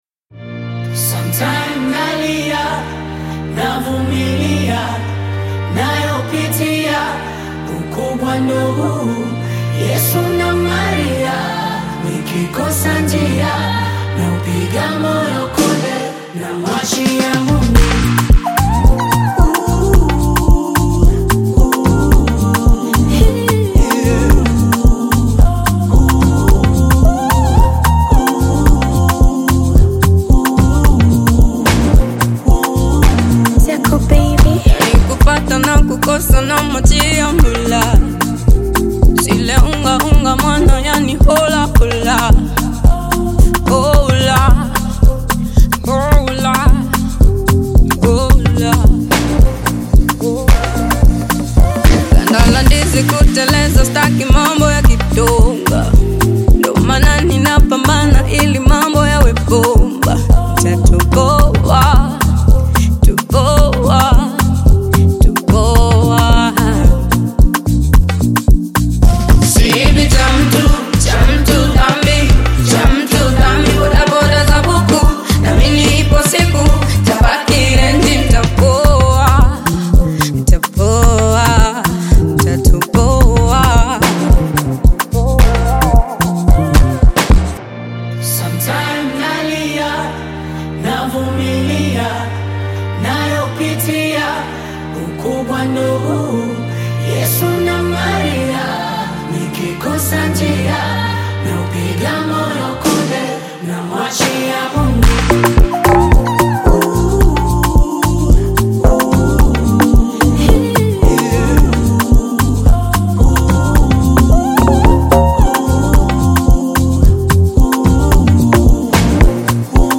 a powerful and inspirational song